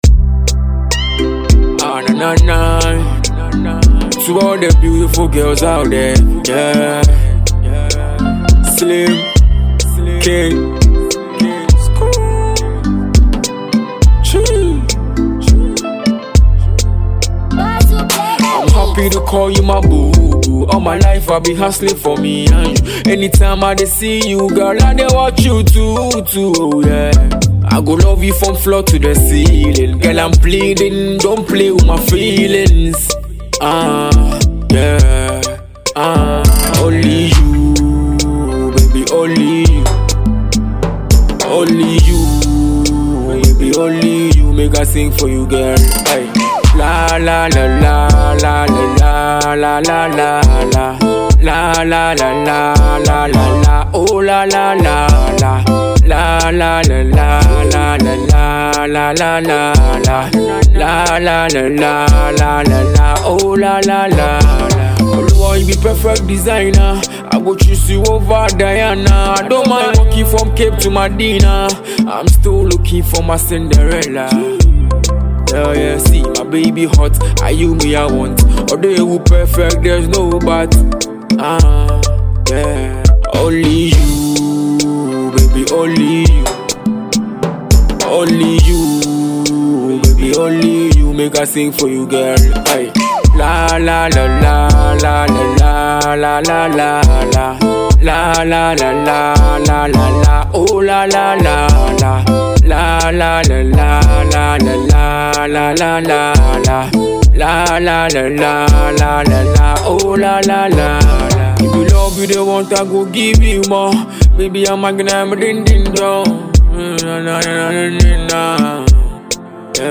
Afropop and Afrobeats singer